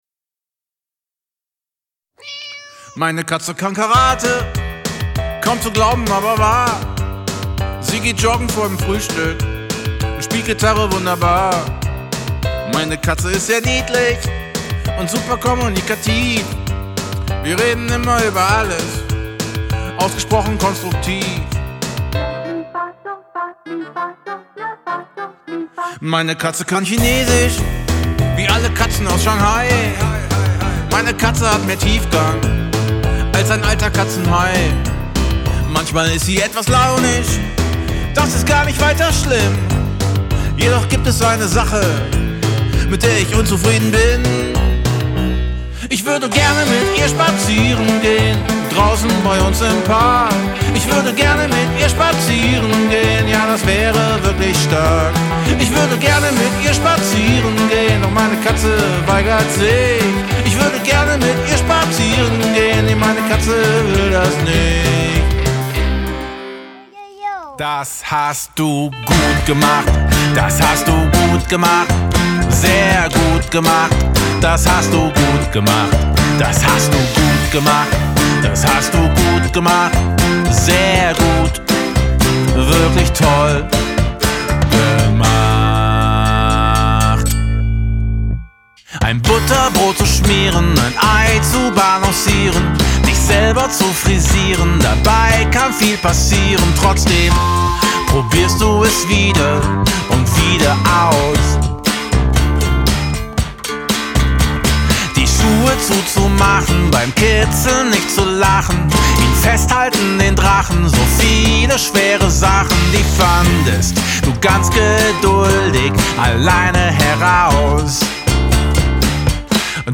Frische, freche Popmusik zum Mitsingen für Kinder.
Kinderlieder
Popmusik für Kinder